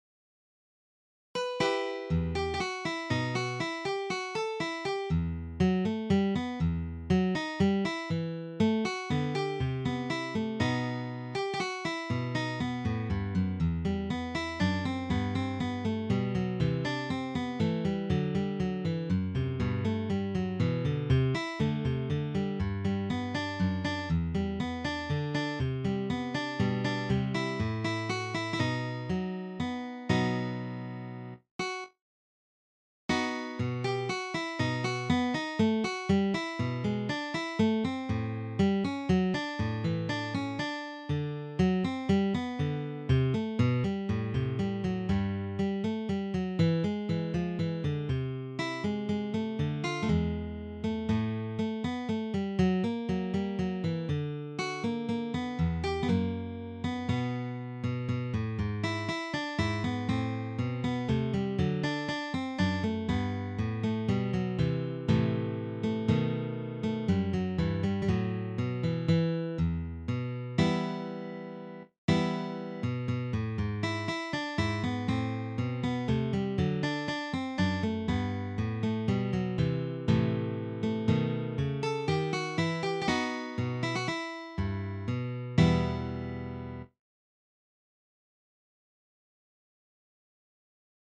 arranged for Guitar Solo